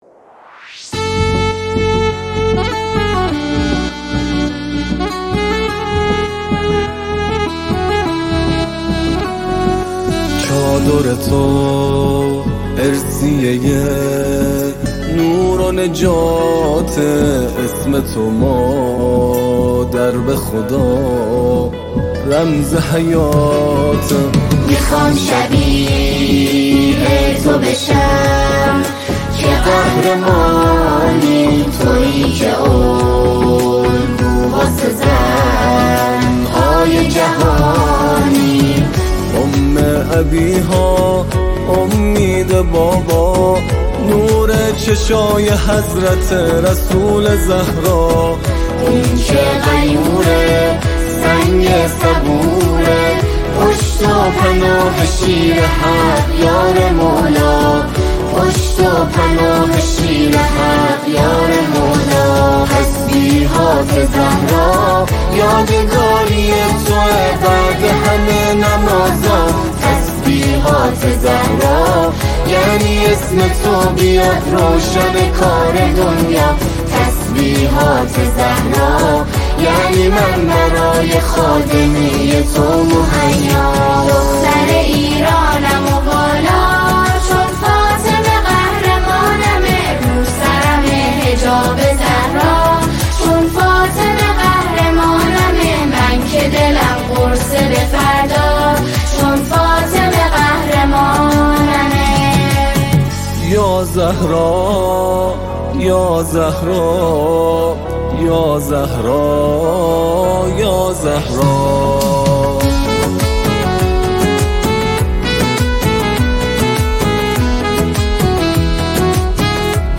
ویژه جشن ولادت حضرت فاطمه سلام الله علیها